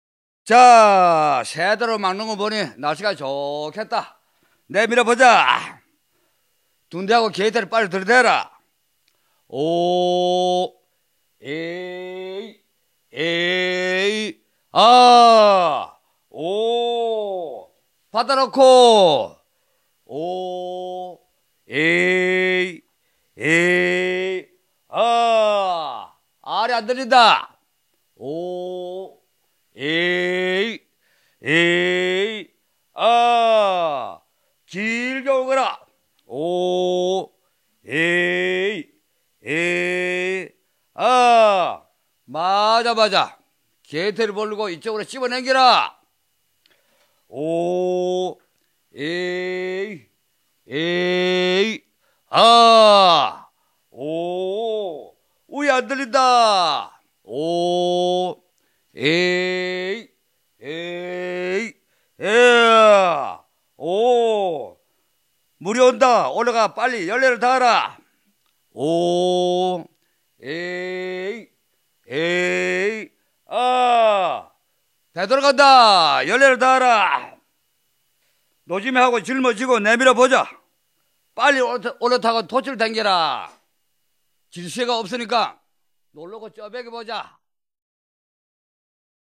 漁夫歌 - 漁業労働歌が珍しい江原道においては、江門をはじめとしてバンバウなどの漁夫歌が1枚のアルバムにまとめられたこと は江原道民謡アルバム事業の注目に値する成果である。